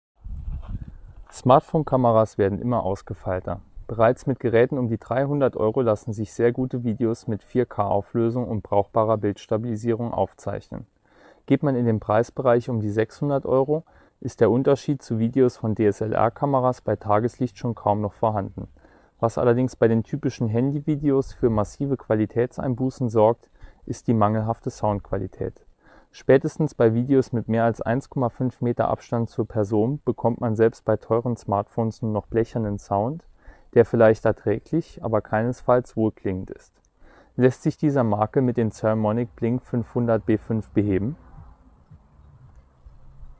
Die Qualität ist auch so noch deutlich besser als von einem normalen Handymikrofon, allerdings ist die Aufnahme im Vergleich mit dem Lavalier doch deutlich flacher.
Testaufnahme mit Transmittermikro draußen mit leichten Nebengeräuschen: